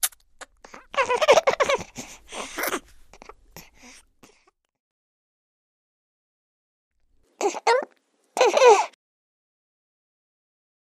Newborn Baby Gulp x2